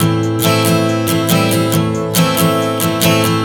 Strum 140 Am 01.wav